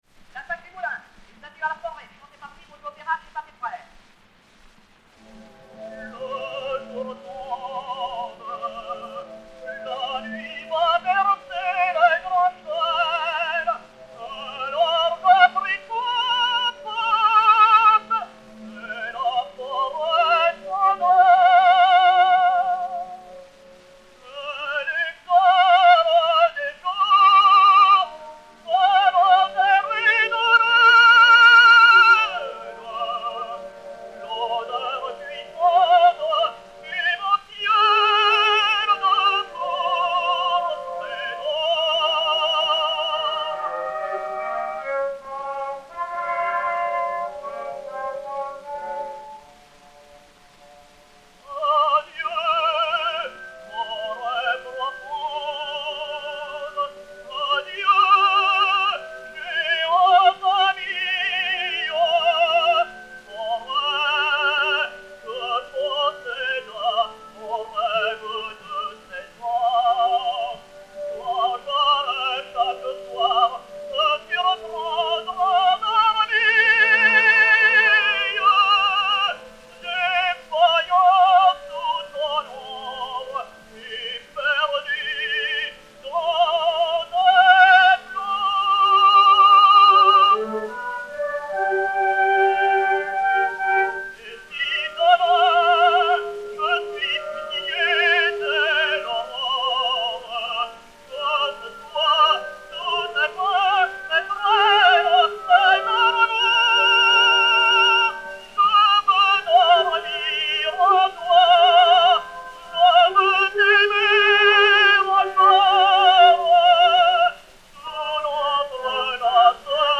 et Orchestre
Pathé saphir 90 tours 4661, enr. à Paris en 1906/1907